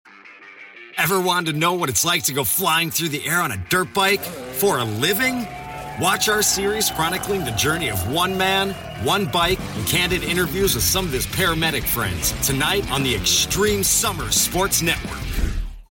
Male
Warm, intelligent American male voice for brands that value trust, clarity, and real human connection. I bring an actor's instinct, musicality, and restraint to every read—delivering confident, natural performances that feel grounded, believable, and never synthetic.
Television Spots